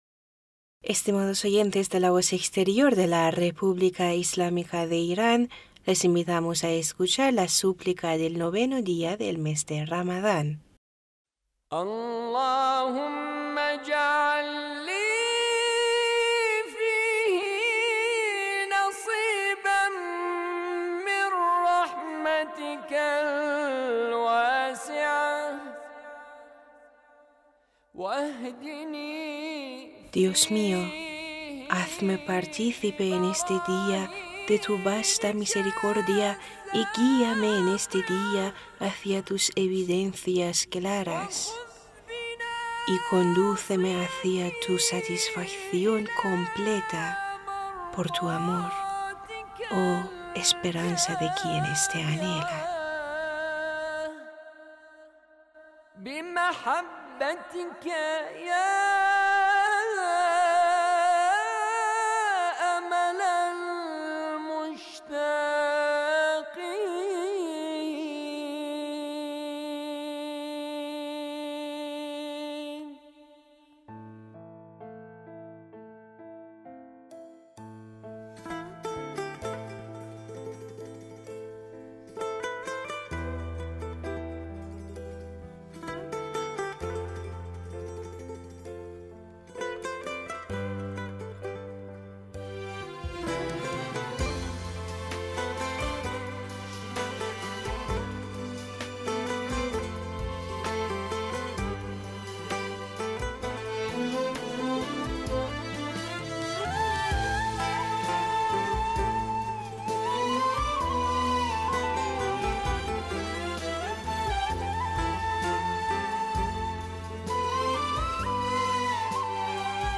Súplica de 9 día de mes de Ramadán